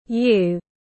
Bạn tiếng anh gọi là you, phiên âm tiếng anh đọc là /juː/.
You /juː/